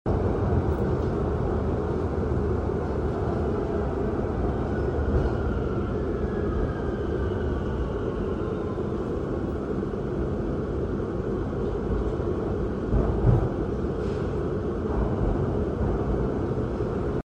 It is so interesting but scary to experience Shanghai metro without a soul left on a sunday night